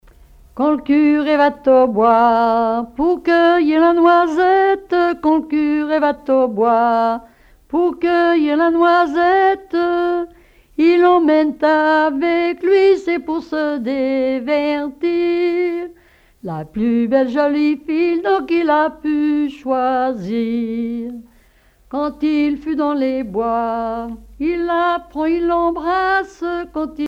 Genre laisse
Témoignages et chansons traditionnelles
Pièce musicale inédite